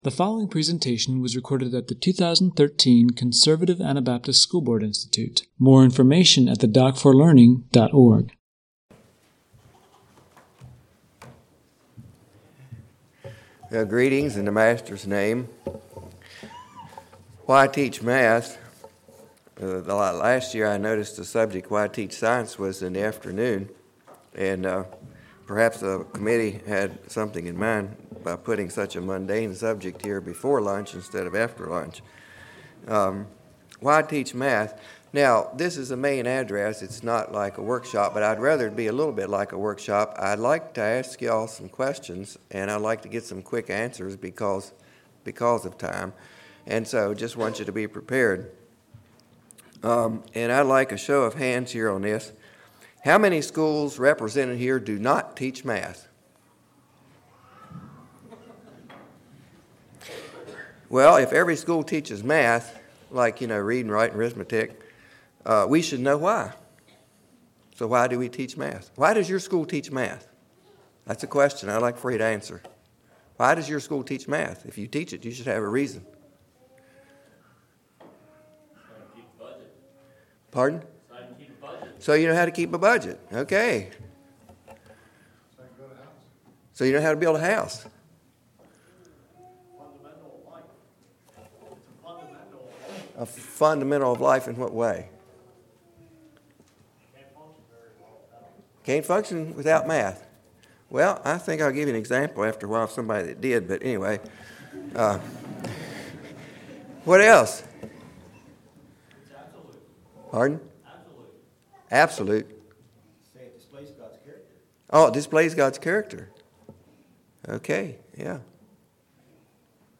Home » Lectures » Why Teach Math?